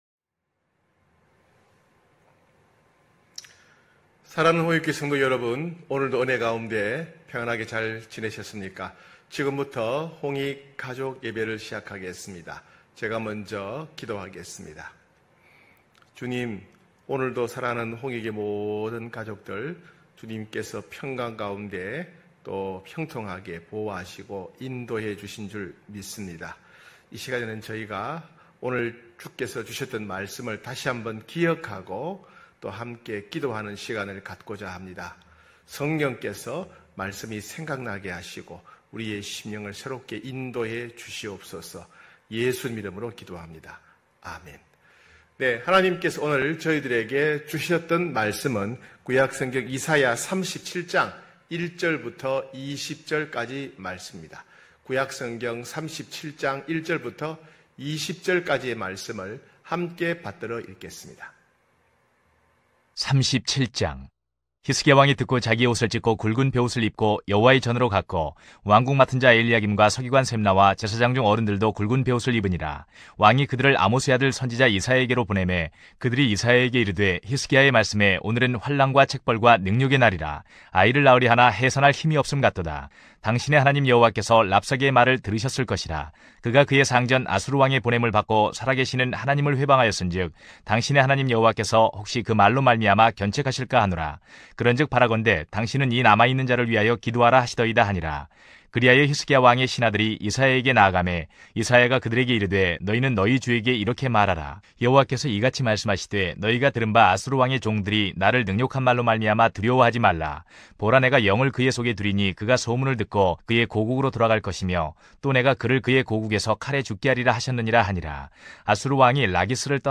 9시홍익가족예배(8월28일).mp3